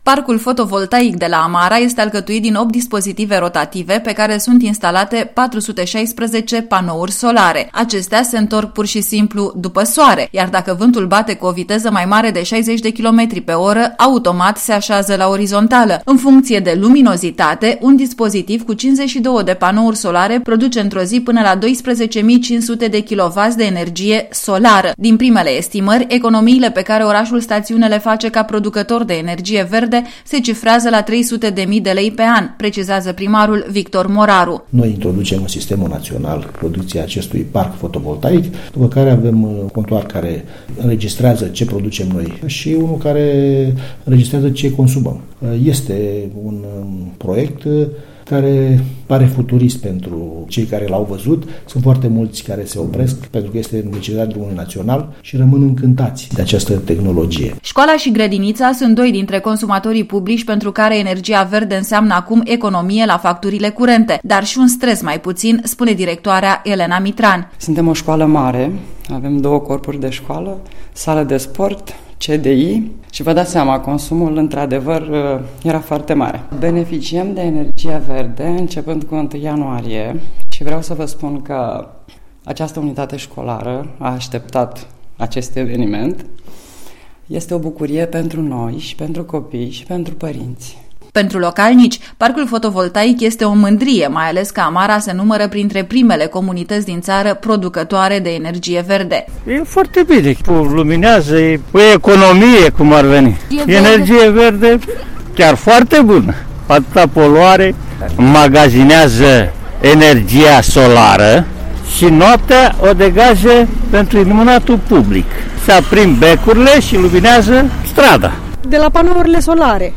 reportaj_parc_fotovoltaic_amara.mp3